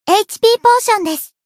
贡献 ） 分类:彩奈 分类:蔚蓝档案语音 协议:Copyright 您不可以覆盖此文件。